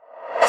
pbs - reverse high [ Sfx ].wav